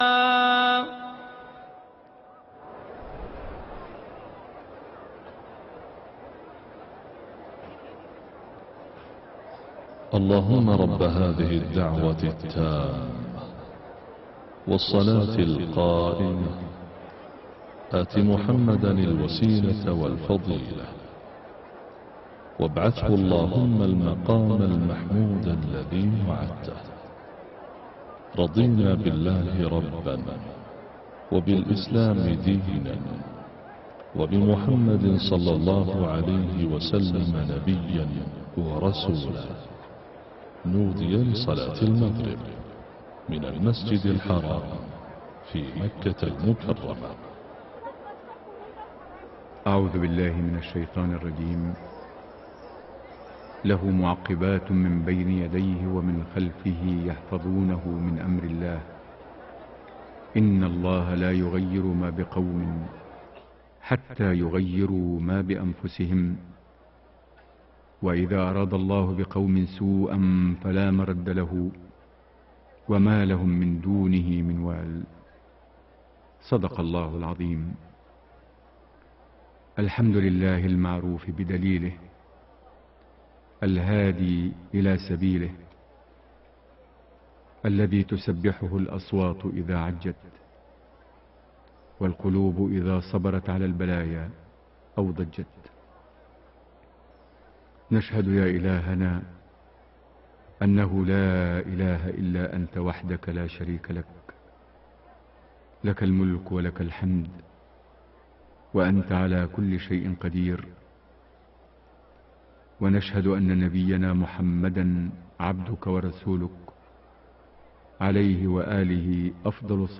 صلاة المغرب 17 ذو الحجة 1432هـ سورتي الليل و النصر > 1432 هـ > الفروض - تلاوات ماهر المعيقلي